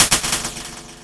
贡献 ） 分类:游戏音效 您不可以覆盖此文件。